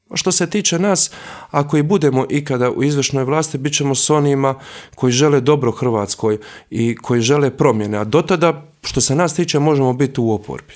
ZAGREB - U ekskluzivnom intervjuu za Media servis predsjednik Mosta i potpredsjednik Sabora Božo Petrov govorio je o slučaju Agrokor ali i Vladi Andreja Plenkovića.